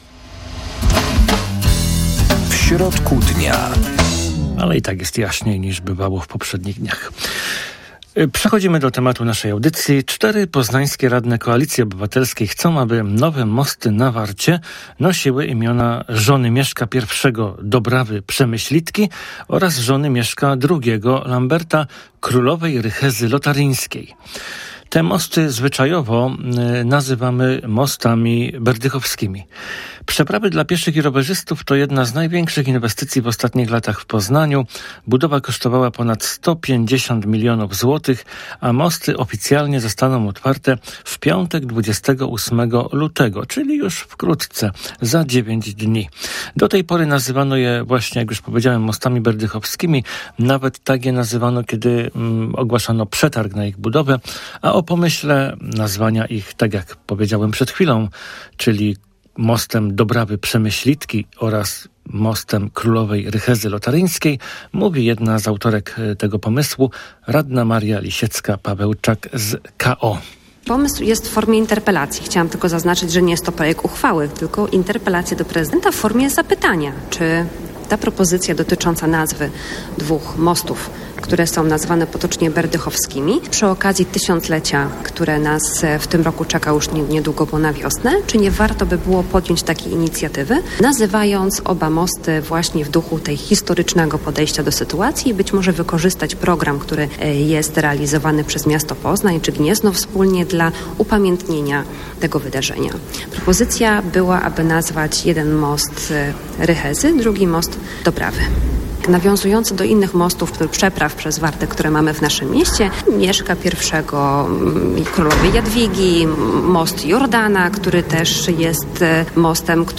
Zapraszamy do rozmowy w audycji „W środku dnia”.